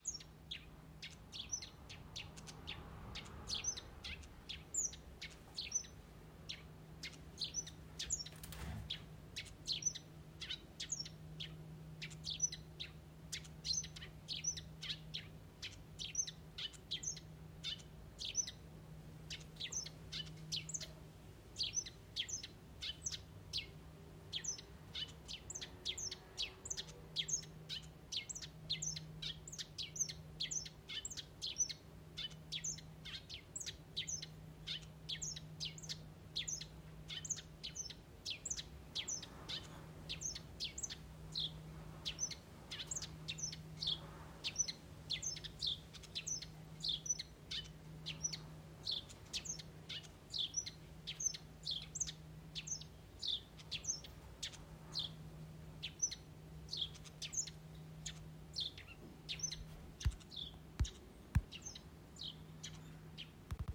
スズメのさえずり
前の家の木の枝でスズメがさえずっていましたと言うか、鳥の本に書いてあったスズメのさえずりではないかと思いますが、皆さんはどう思いますか？